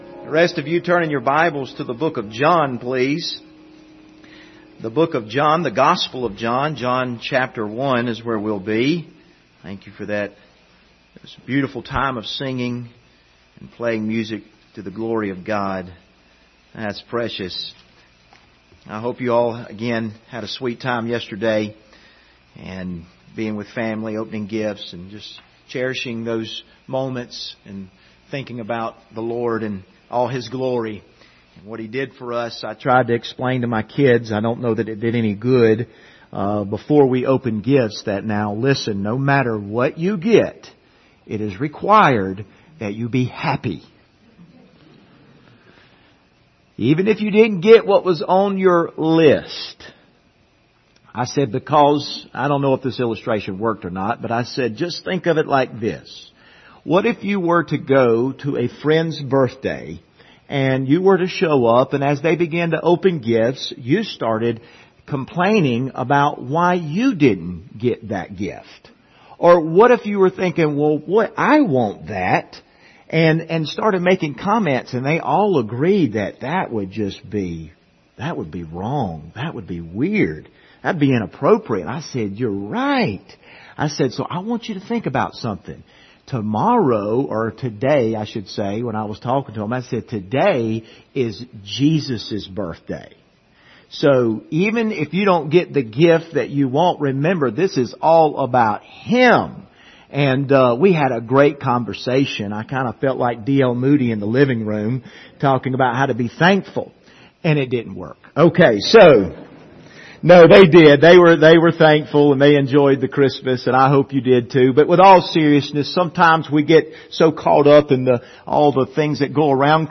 Passage: John 1:1-18 Service Type: Sunday Morning Topics